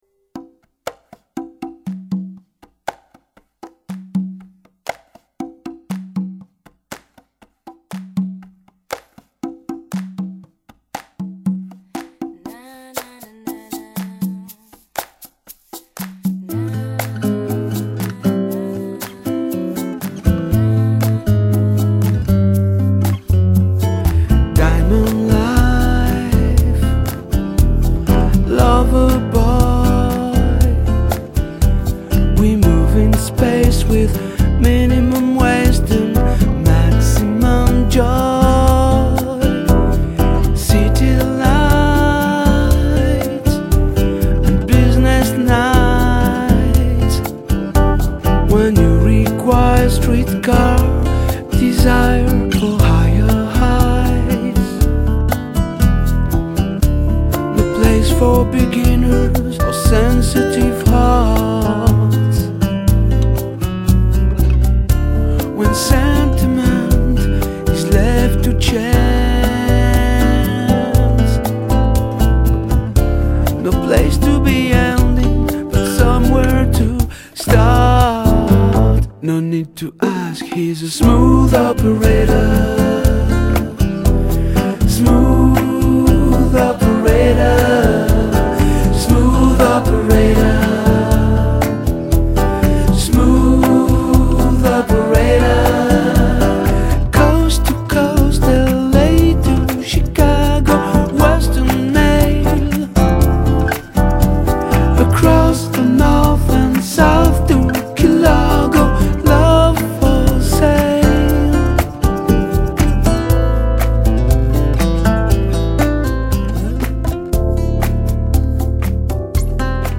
une ballade dans le style smooth jazz